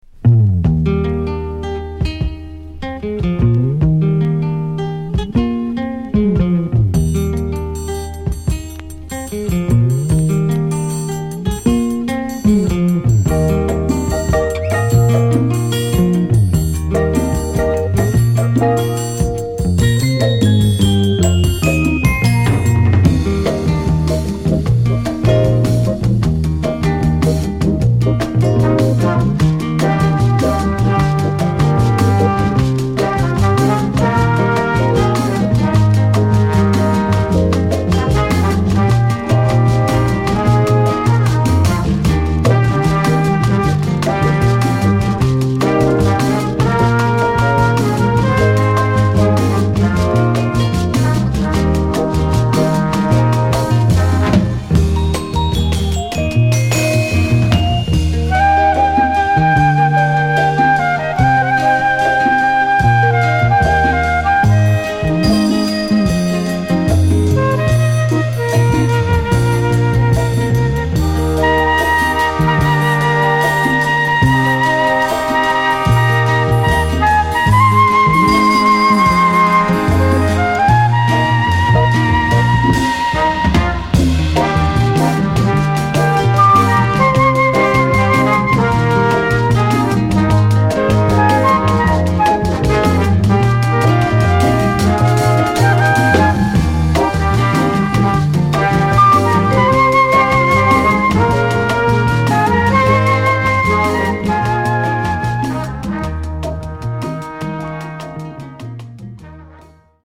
いかにも英国らしいイージーかつ上品なサウンドと、程よいグルーヴ感が心地良く響く一枚です。